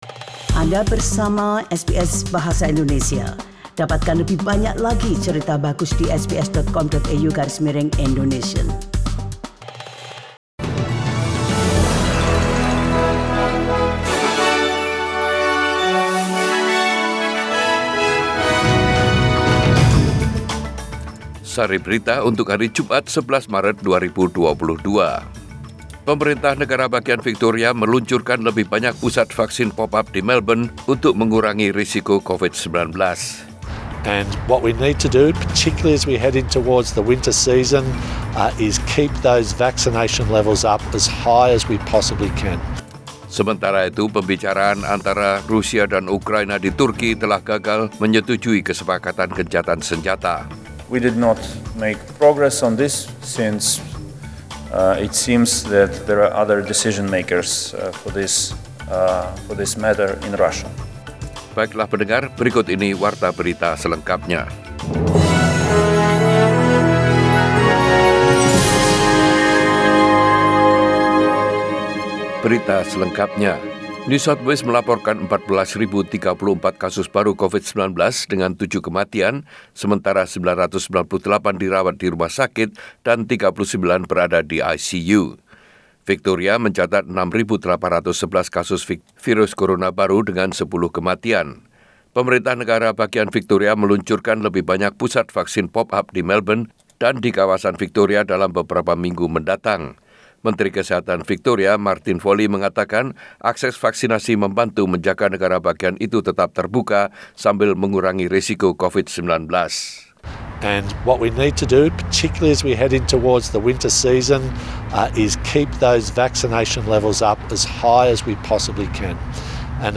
SBS Radio News in Bahasa Indonesia - 11 March 2022